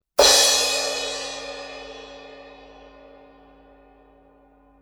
パワフルで抜けの良いサウンドでスタジオ、ライブハウスの定番クラッシュシンバル。
キレのある、芯の強いサウンドは、多くの人々から支持を集めています。